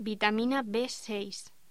Locución: Vitamina B6